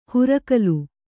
♪ hurakalu